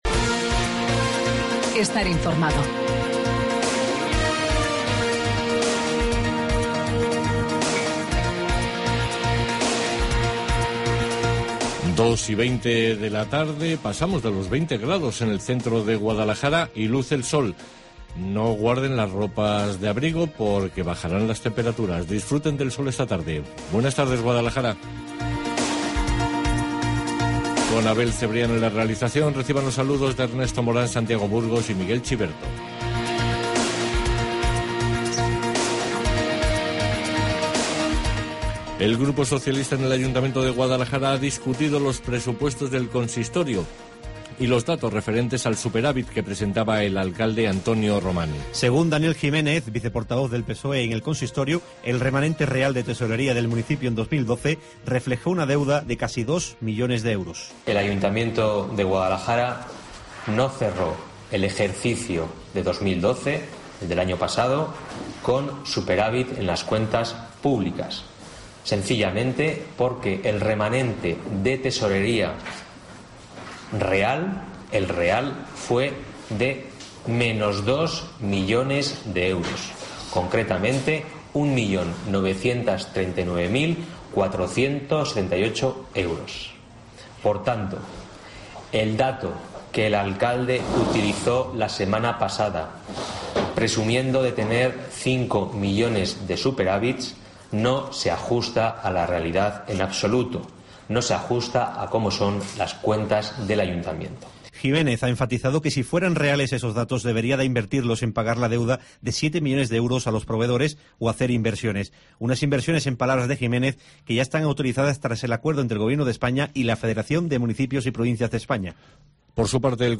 Informativo Guadalajara 24 de abril